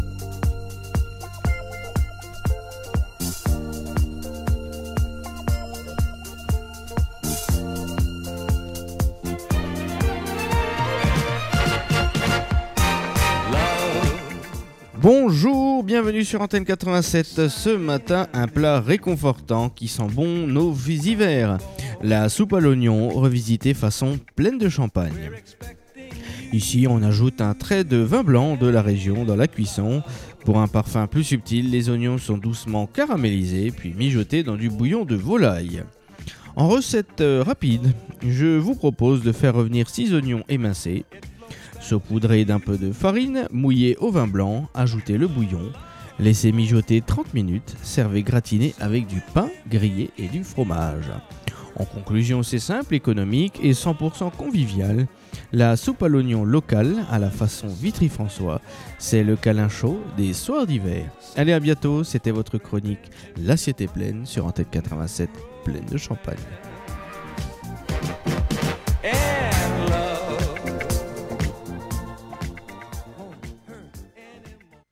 Chaque semaine sur Antenne 87, partez à la découverte d’un plat typique ou d’une recette oubliée des Plaines de Champagne, dans la chronique culinaire "L’Assiette est Plaines".